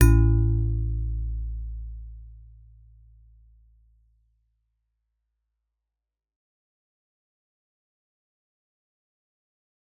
G_Musicbox-A1-f.wav